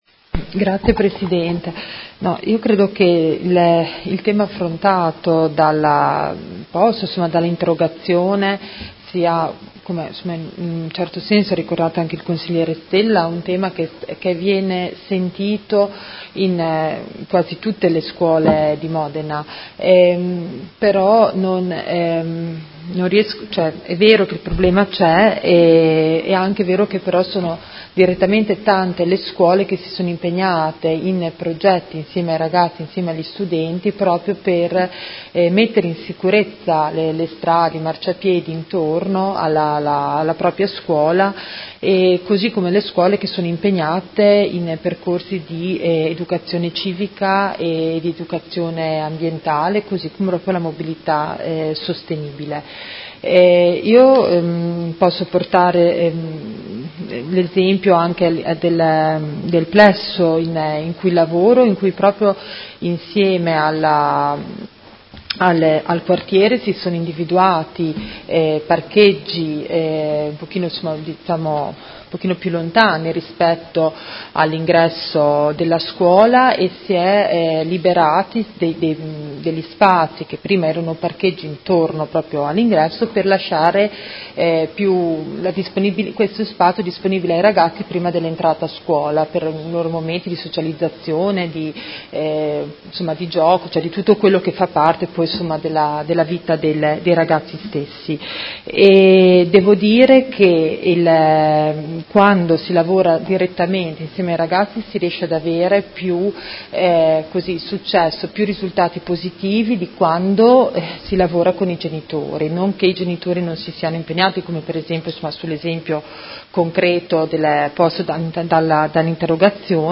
Grazia Baracchi — Sito Audio Consiglio Comunale
Seduta del 17/01/2019 Dibattito. Interrogazione dei Consiglieri Carpentieri e Baracchi (PD) avente per oggetto: Viabilità in Via Frescobaldi – accesso al Polo scolastico.